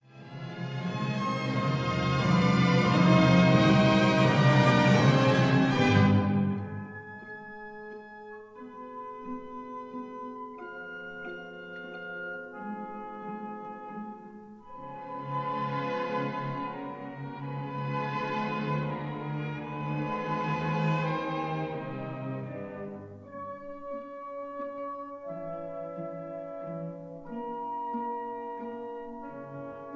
stereo recording